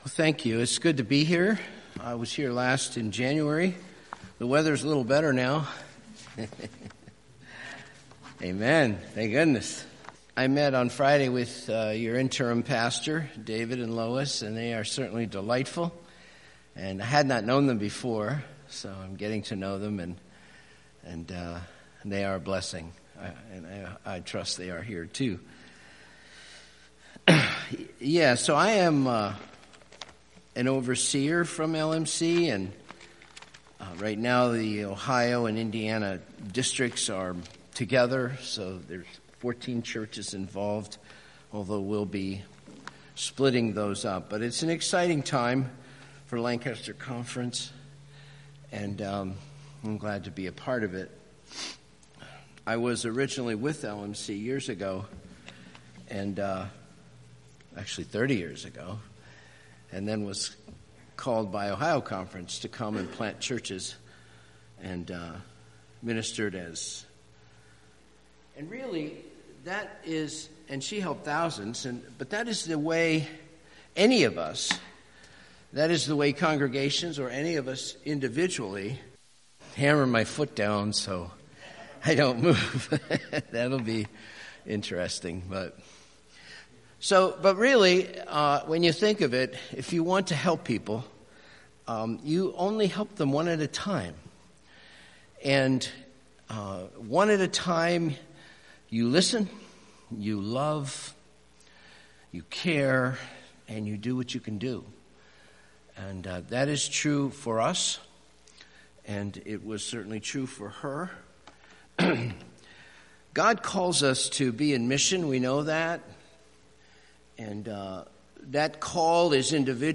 Passage: John 21:1-19 Service Type: Sunday Morning